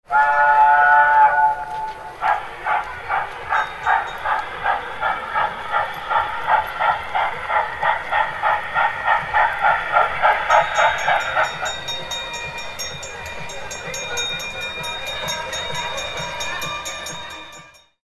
Tourist Train Approaching With Horn And Bell Ringing Sound Effect
Description: Tourist train approaching with horn and bell ringing sound effect. Arrival of the tourist train with passengers, accompanied by distant train horn honking and ringing railway bells.
Genres: Sound Effects
Tourist-train-approaching-with-horn-and-bell-ringing-sound-effect.mp3